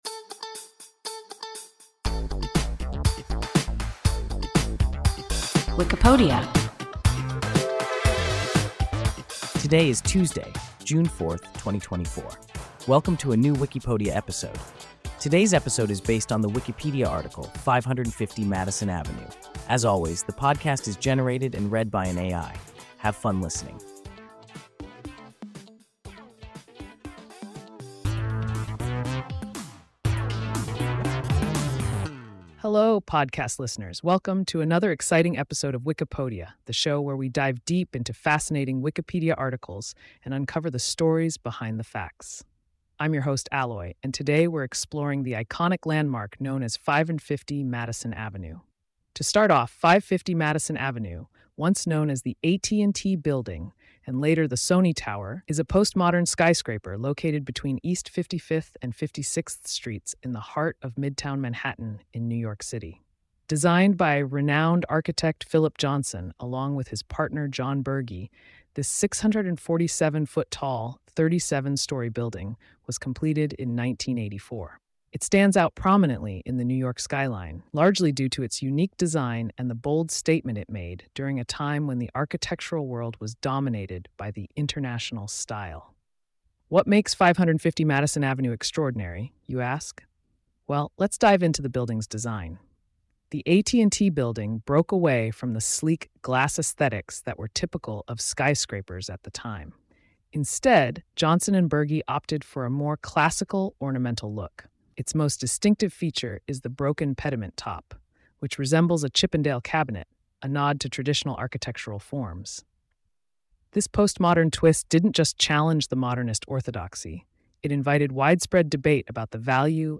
550 Madison Avenue – WIKIPODIA – ein KI Podcast